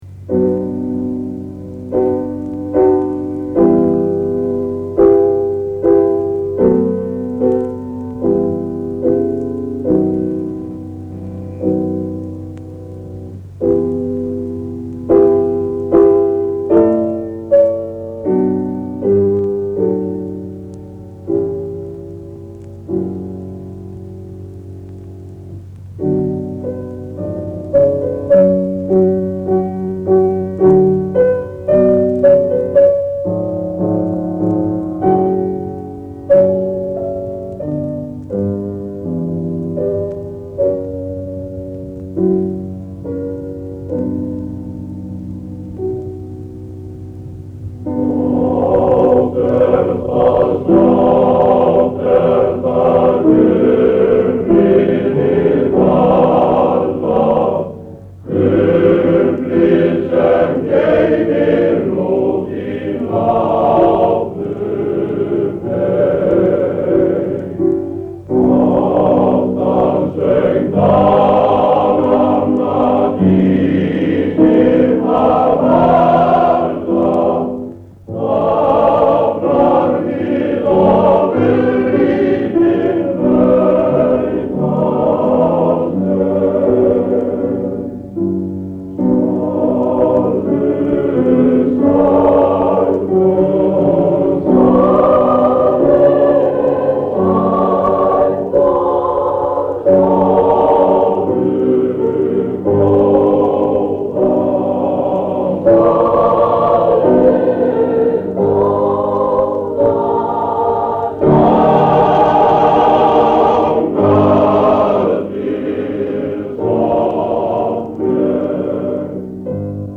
Höf stjórnar á æfingu.Tvöfaldur kór.